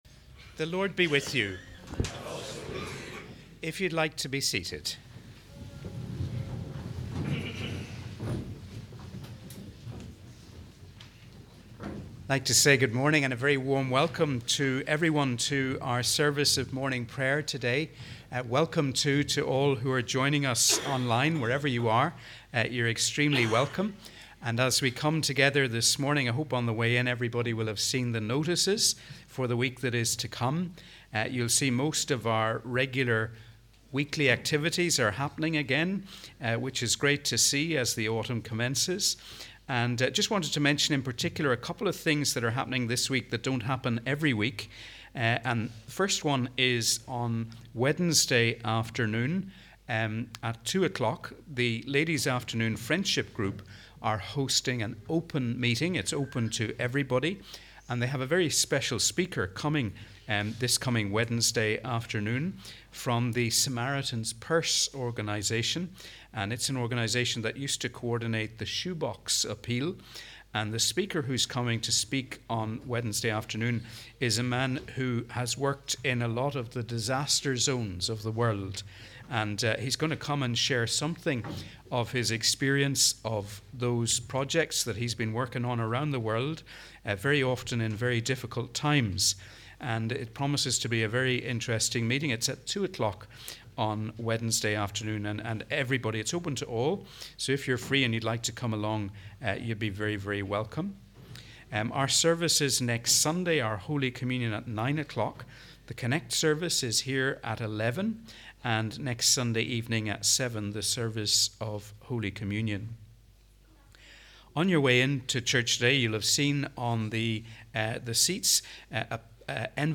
Welcome to our service of Morning Prayer for the 16th Sunday after Trinity.